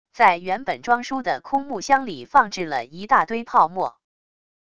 在原本装书的空木箱里放置了一大堆泡沫wav音频